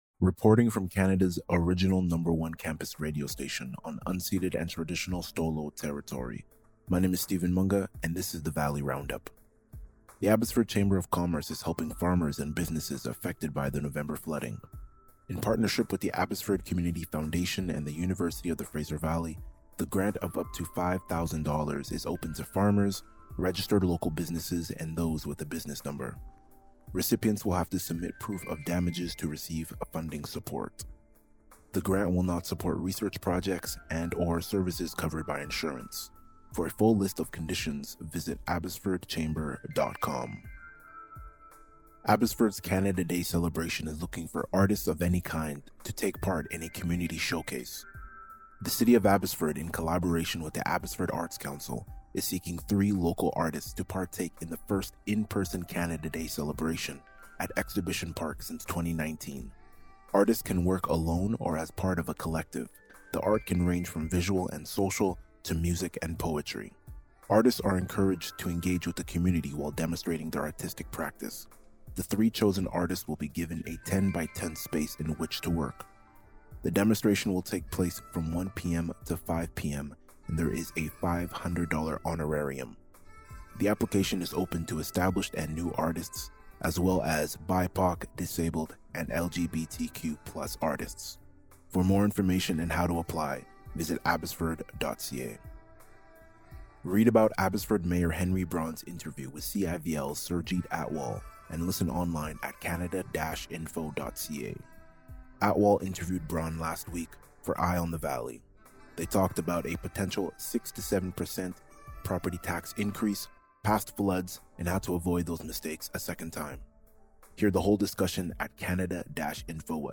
Reporting from Canada’s original #1 campus radio station, on unceded and traditional stó:lō territory.